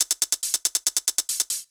UHH_ElectroHatA_140-03.wav